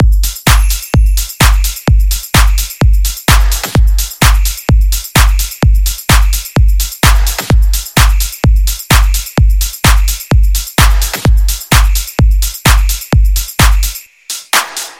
描述：一个带有一些混响的拍子，让它听起来更酷。
标签： 128 bpm House Loops Drum Loops 1.26 MB wav Key : Unknown
声道立体声